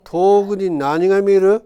Aizu Dialect Database
Type: Single wh-question
Final intonation: Rising
WhP Intonation: Peak
Location: Aizuwakamatsu/会津若松市
Sex: Male